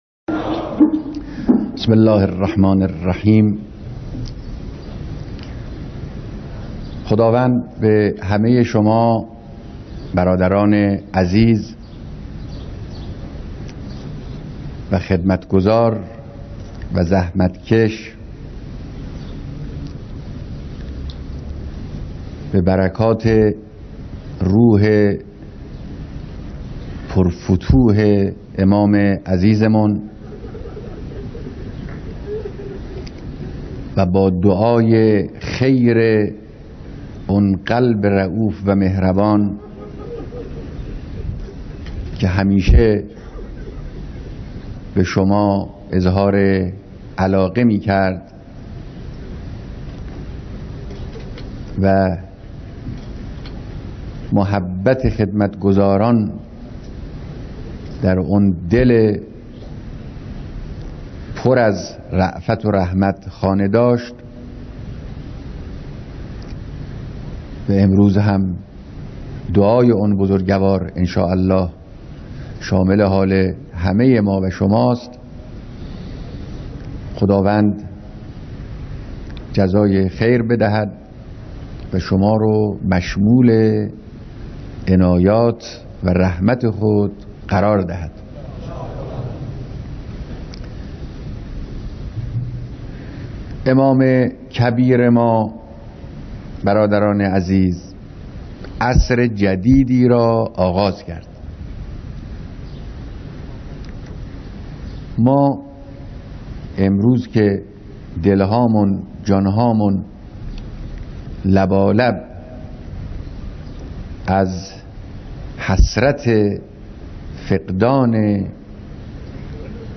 بیانات در مراسم بیعت نیروهای ژاندارمری و شهربانی و سازمان پلیس قضایی